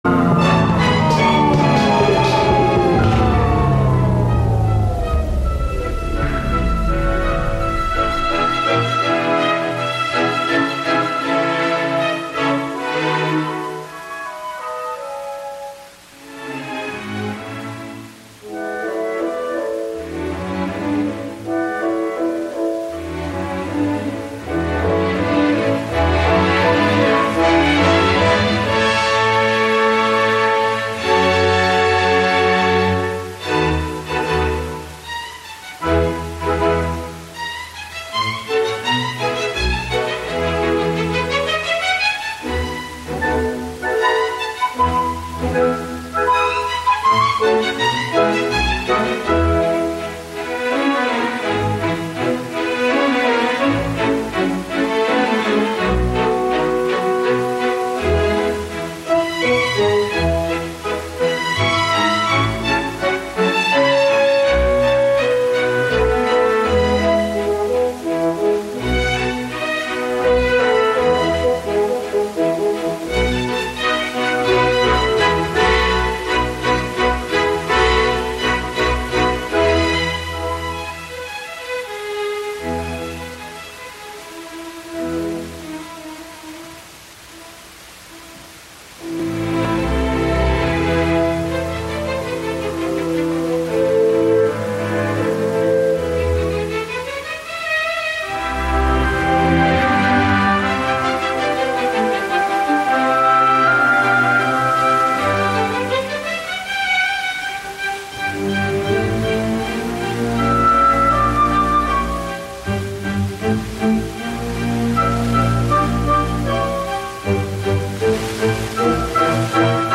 11am Live from Brooklyn, New York
instant techno